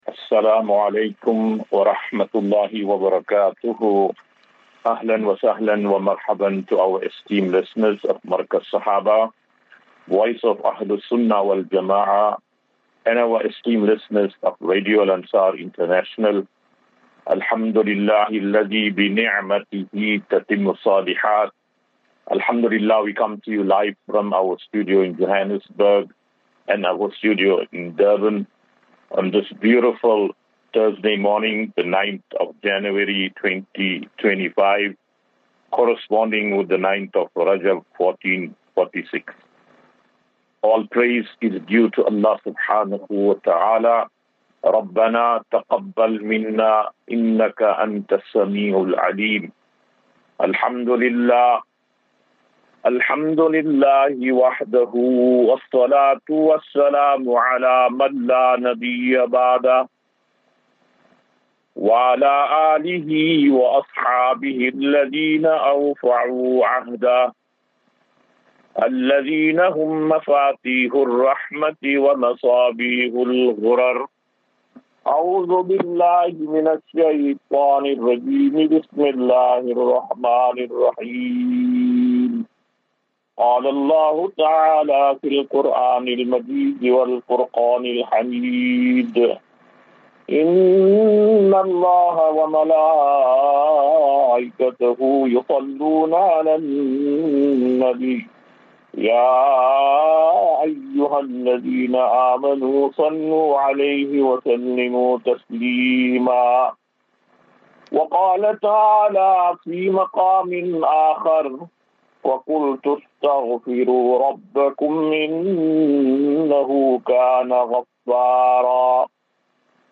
9 Jan 09 January 2025. Assafinatu - Illal - Jannah. QnA